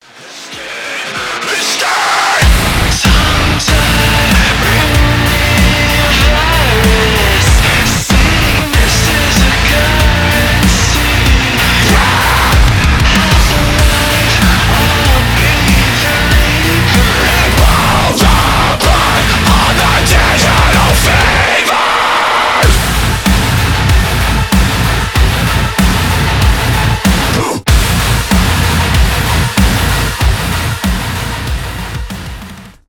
metalcore